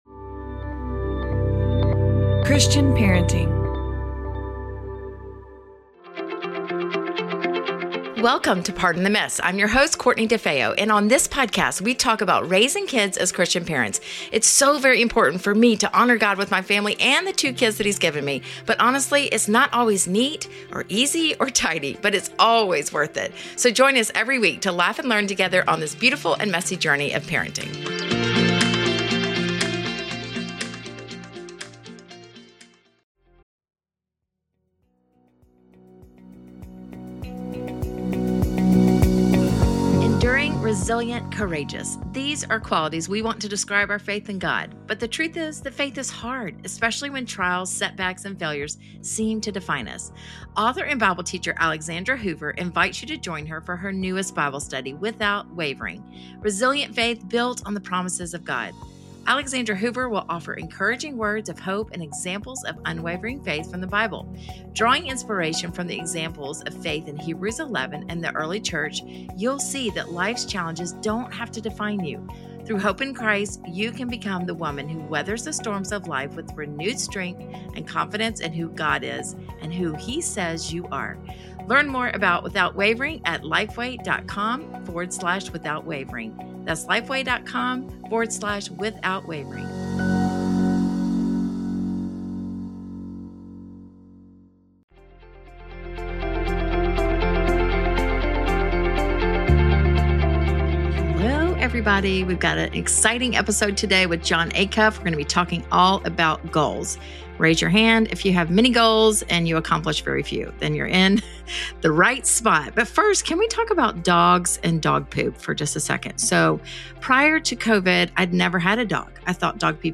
I was so delighted to interview a New York Times bestselling author and INC Top 100 Leadership Speaker about the idea of goals.